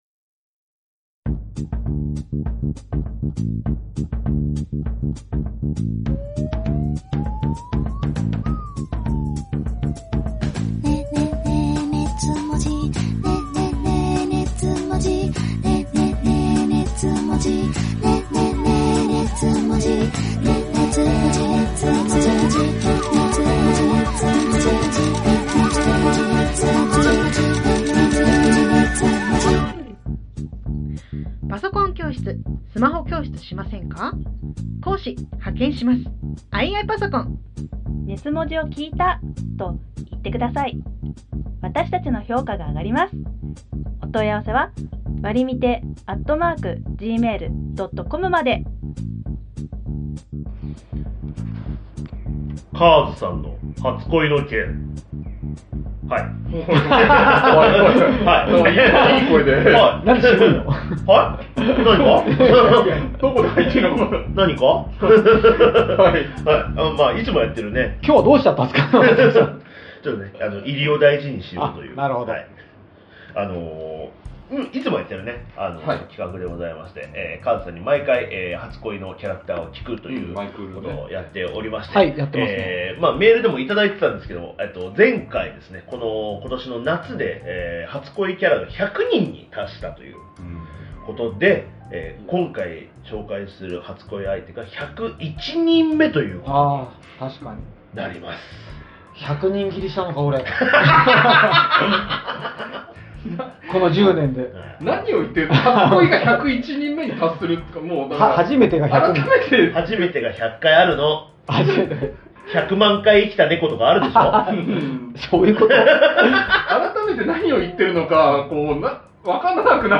二次元を哲学するトークバラエティ音声マガジン